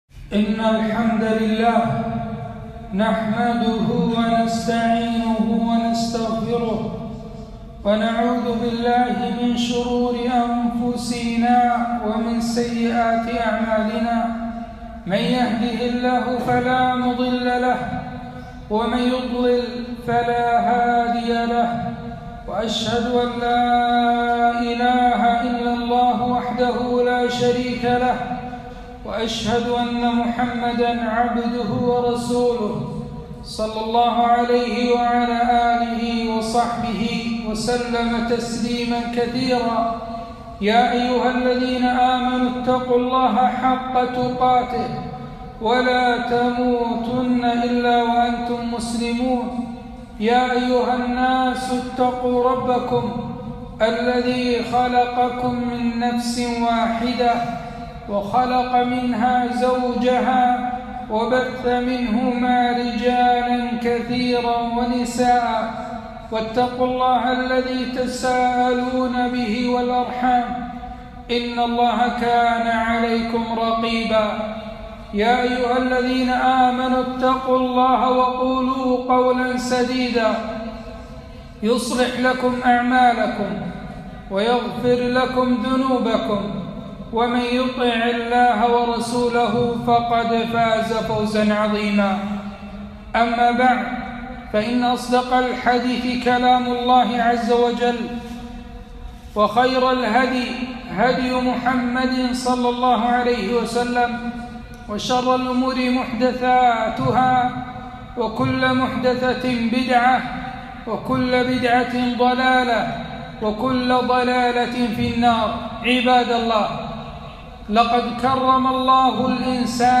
خطبة - المخدرات ضياع ودمار